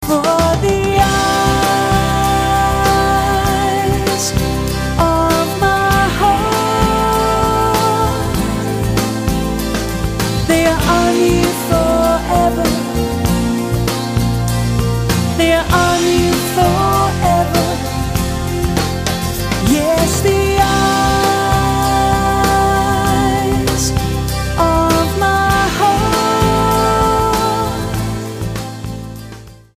STYLE: Pop
recorded in a well-produced but fairly simplistic style